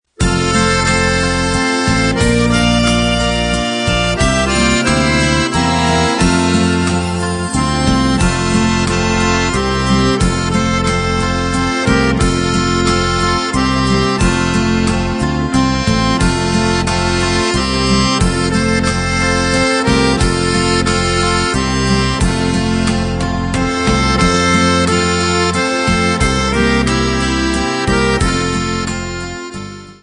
Besetzung: Akkordeon mit CD